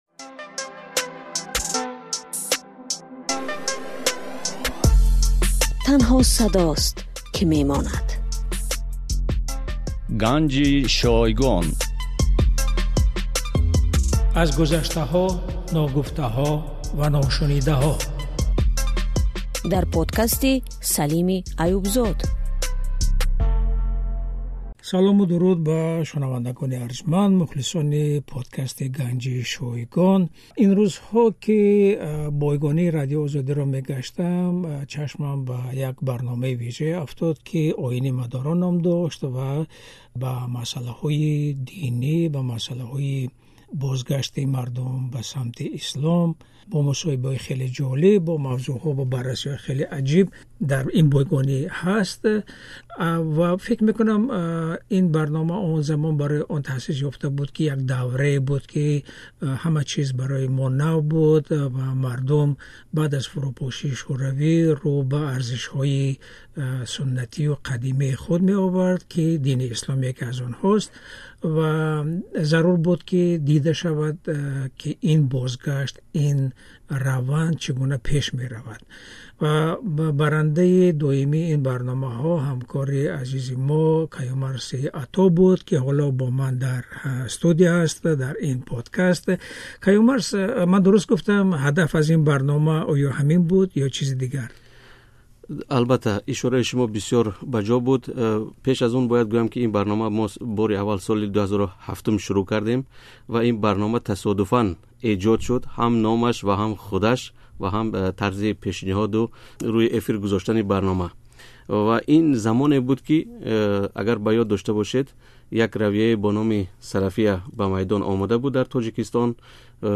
Бо як суҳбат дар бораи таъсис ёфтани ин барнома ва овардани нахустин нашри он дар бораи майли шаҳрвандони Тоҷикистон ба ҳаҷ мавзуъро ба ҳаёти имрӯзи кишвар рабт медиҳем.